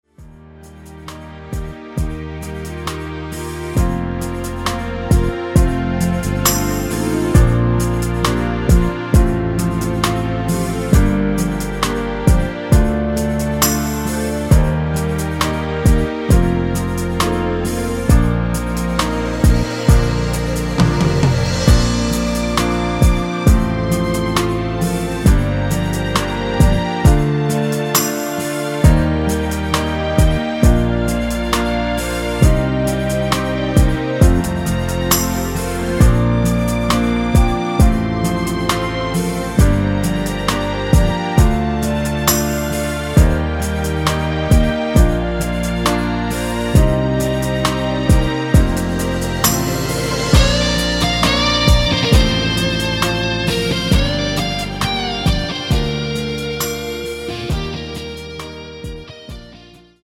엔딩이 페이드 아웃이라 노래 하시기 좋게 엔딩을 만들어 놓았습니다.
Bb
멜로디 MR이라고 합니다.
앞부분30초, 뒷부분30초씩 편집해서 올려 드리고 있습니다.